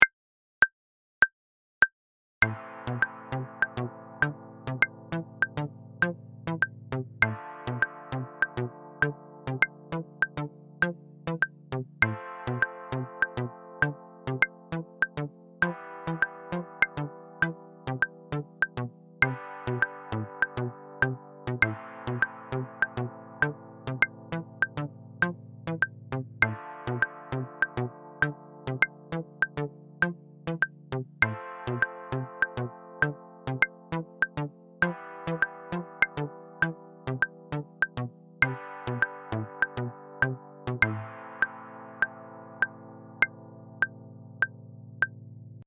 Dotted 8th note groove displacement PDF and mp3s.